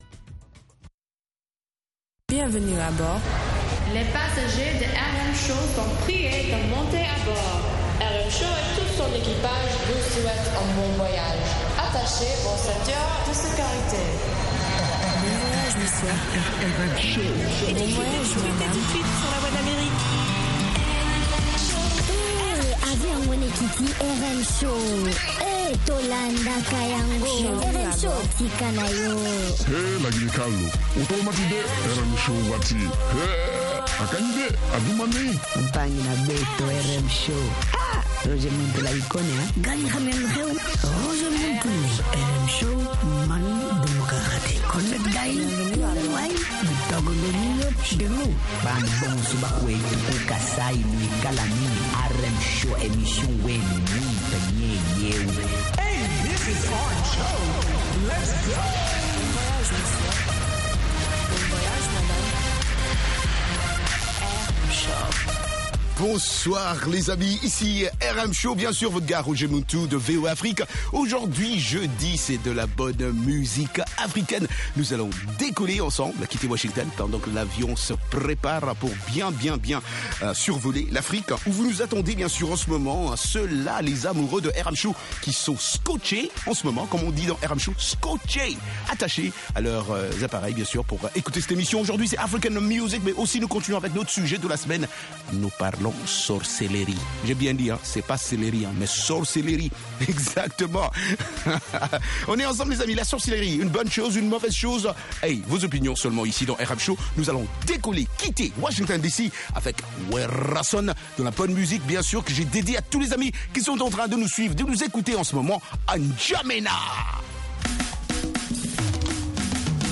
propose notamment d'écouter de la musique africaine, des articles sur l'actualité Afro Music, des reportages et interviews sur des événements et spectacles africains aux USA ou en Afrique.